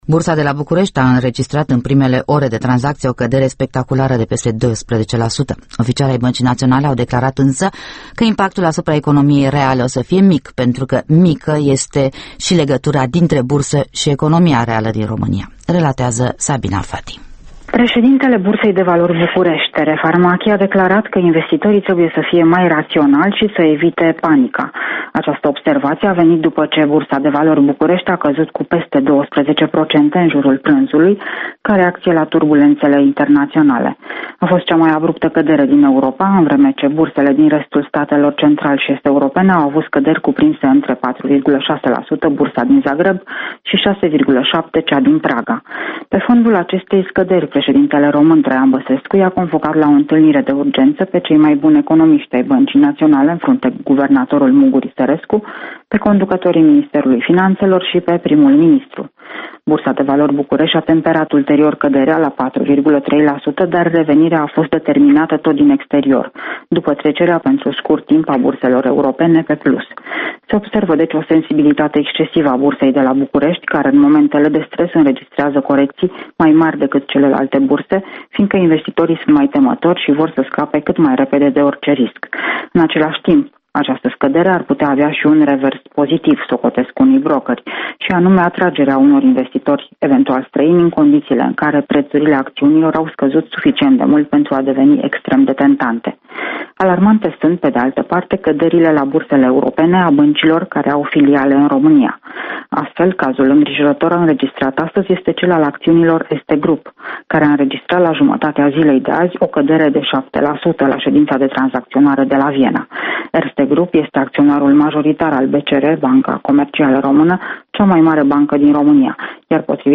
Corespondenţa zilei de la Bucureşti